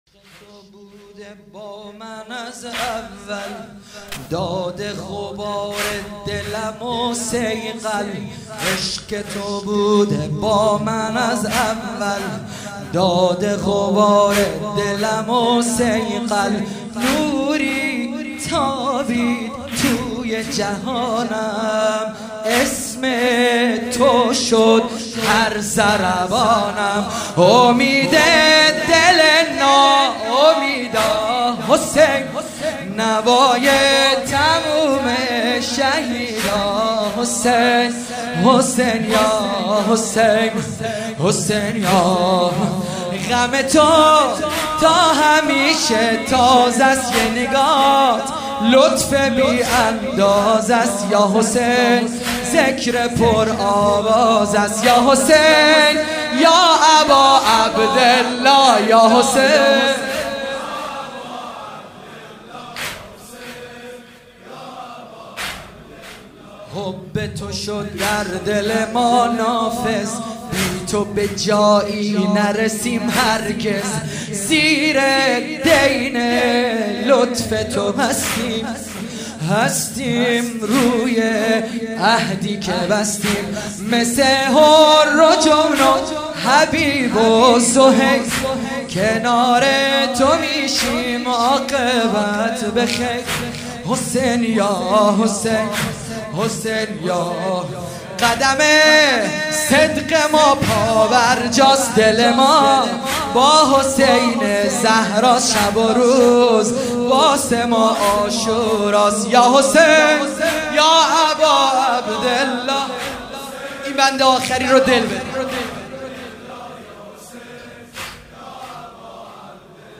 سنگین | عشق تو بوده با من از اول
مراسم عزاداری شب سوم محرم 1441 هجری قمری | هیأت علی اکبر بحرین